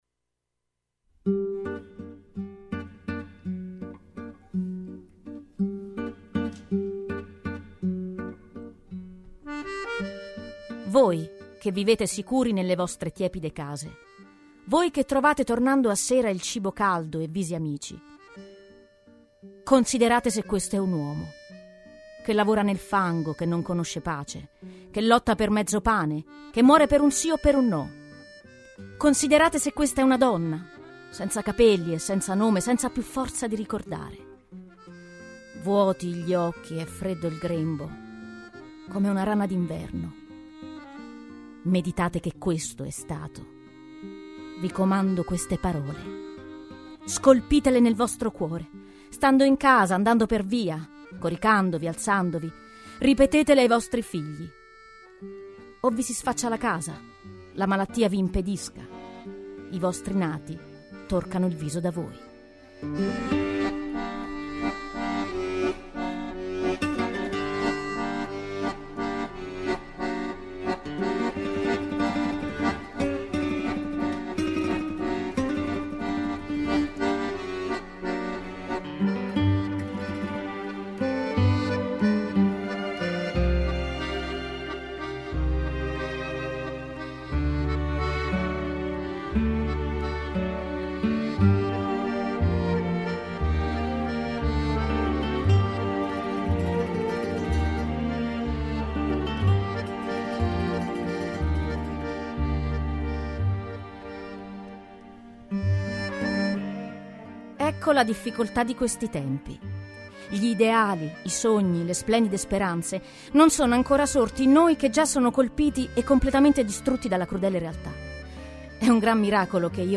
Musica: Paul Cantelon – Inside out
Musca: Moby – Everloving